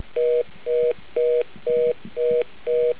Dialing a non-existent local will give the "fast busy" error tone Hang up.